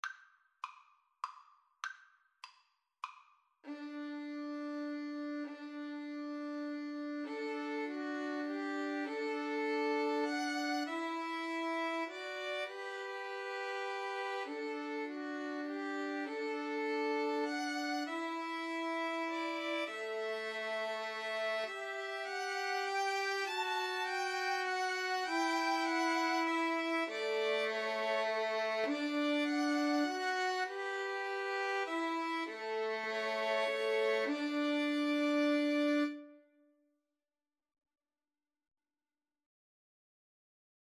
D major (Sounding Pitch) (View more D major Music for Violin Trio )
3/4 (View more 3/4 Music)
Moderato